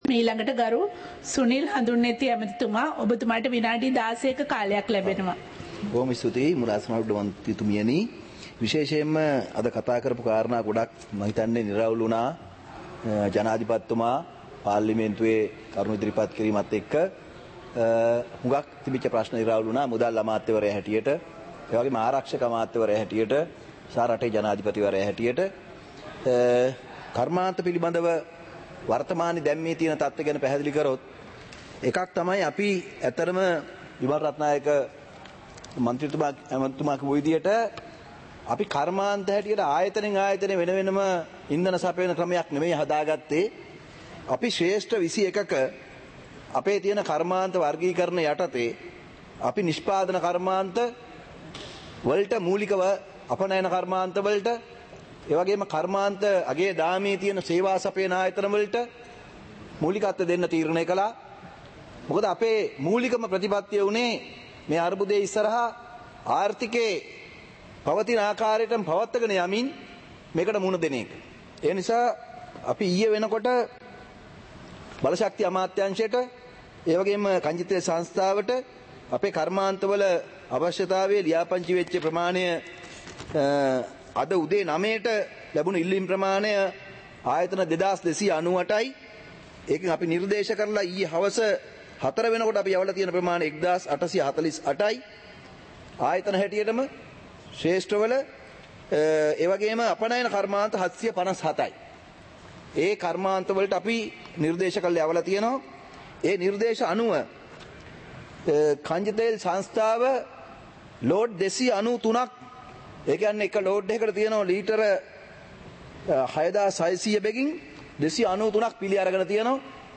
සභාවේ වැඩ කටයුතු (2026-03-20)
පාර්ලිමේන්තුව සජීවීව - පටිගත කළ